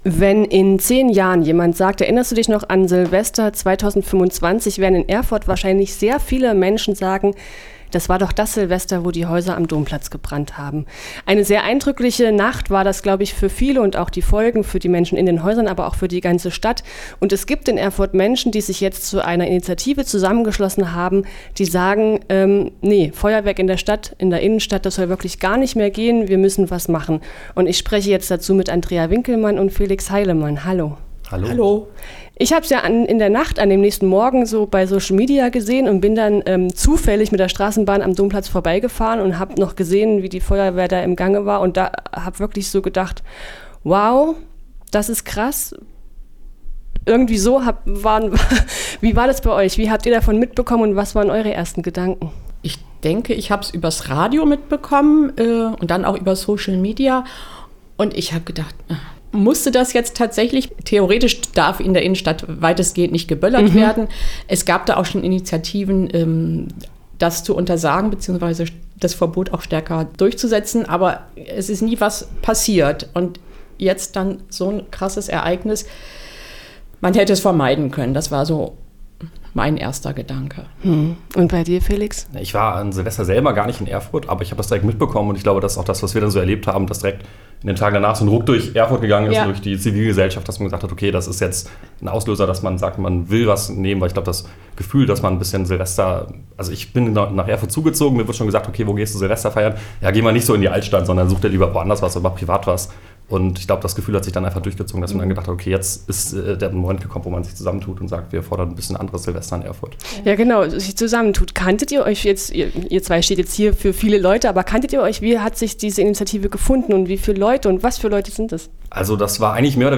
Eine Initiative aus ganz unterschiedlichen Menschen will nun mittels eines Einwohnerantrags erreichen, dass eine umfassende Sperrzone für Pyrotechnik eingerichtet und v.a. auch gut kommuniziert und durchgesetzt wird. Im Gespräch mit Radio F.R.E.I.